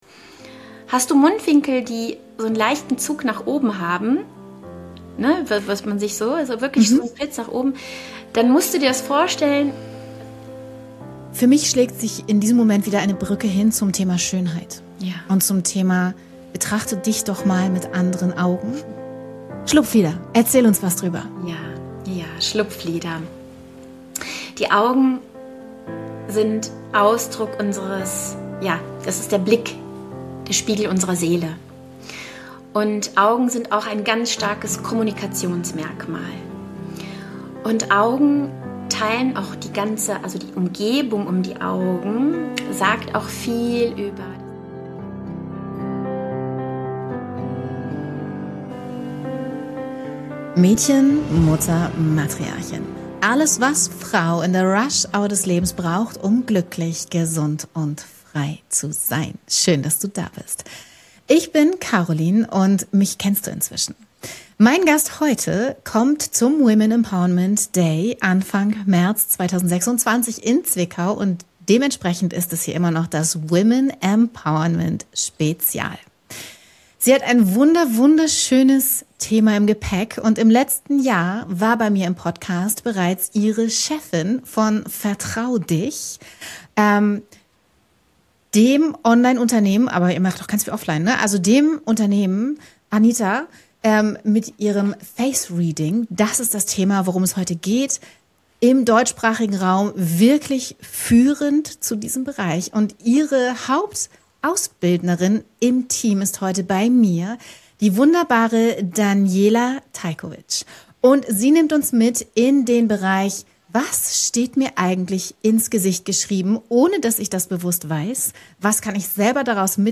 Dieses Interview ist ein Akt weiblicher Rückeroberung.